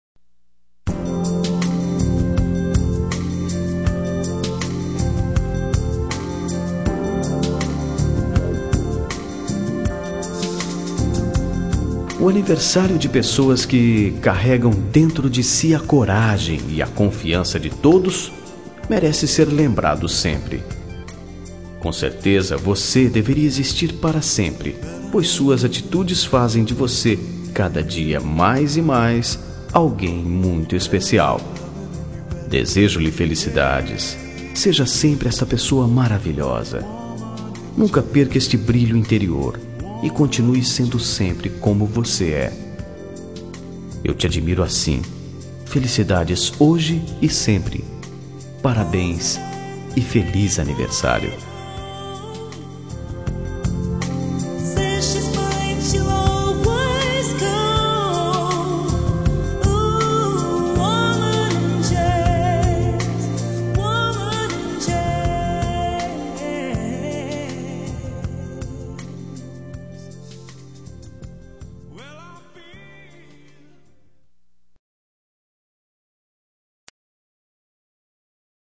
Telemensagem Aniversário de Amiga – Voz Masculina – Cód: 1589